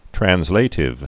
(trăns-lātĭv, trănz-, trănslə-tĭv, trănz-)